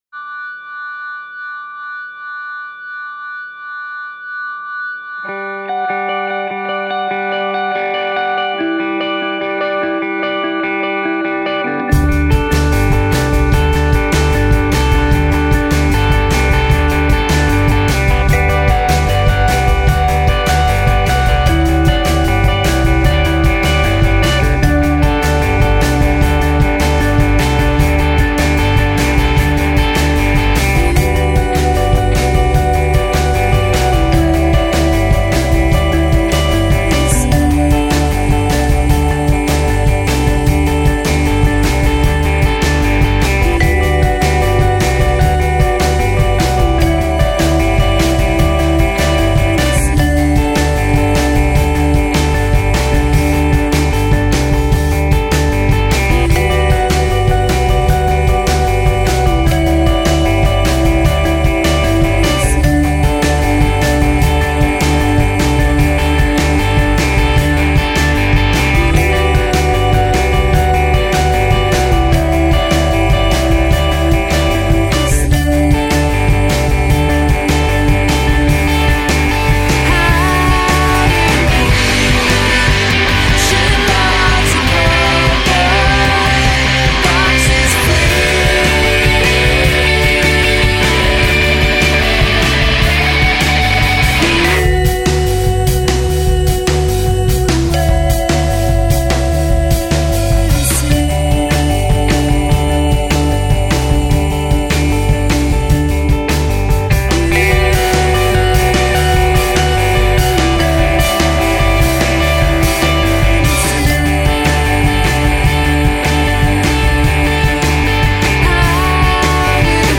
ranging from mellow vibraphone pop
to straight-forward indie rock